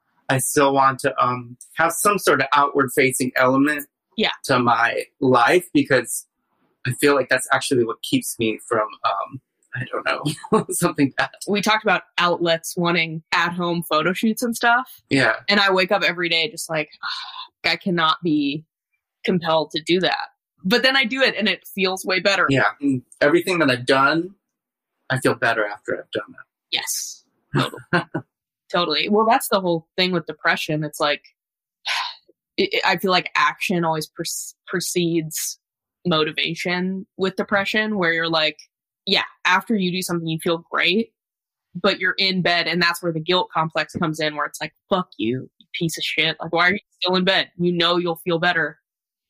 Play Rate Exclusive Listened List Bookmark Share Get this podcast via API From The Podcast Butt Dial 1 Late night chats between artists, where the strange and mundane live in perfect harmony.